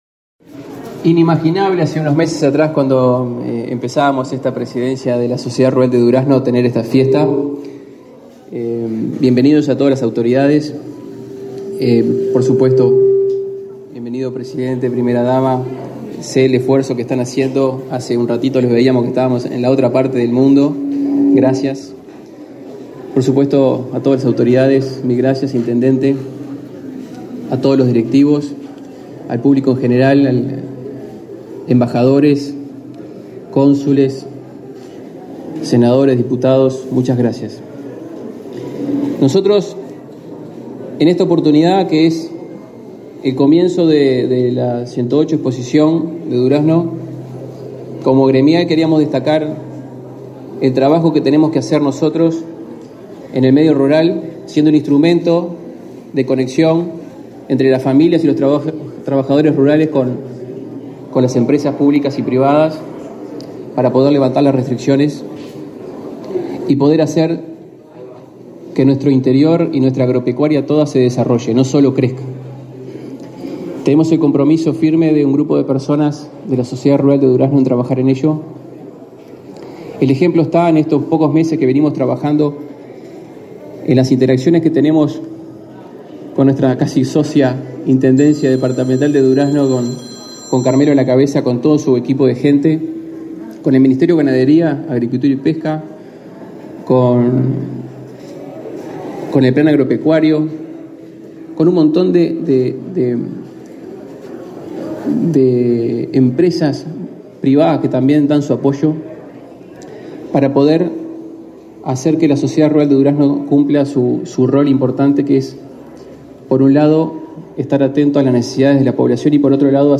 Conferencia de prensa por la inauguración de la 108.ª Expo Durazno